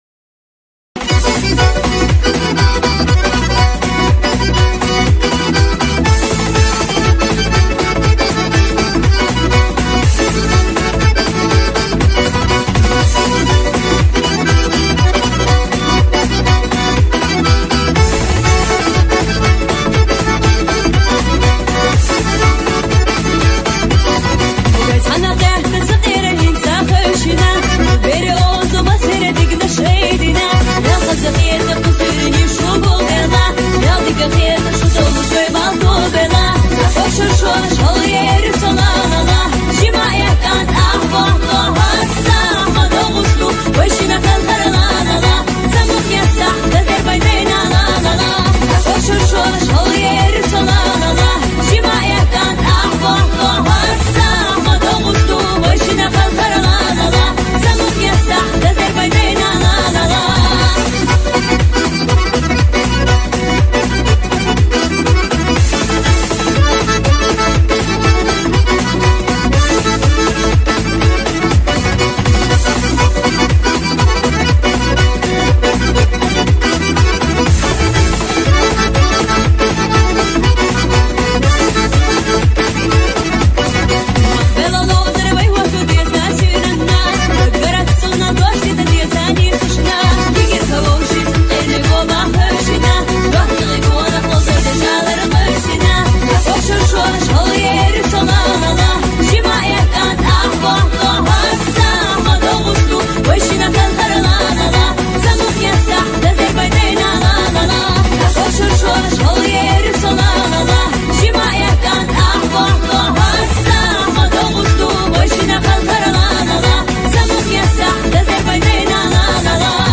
Чеченская Музыка